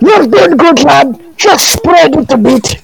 demoman_cloakedspyidentify02.mp3